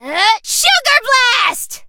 mandy_ulti_vo_01.ogg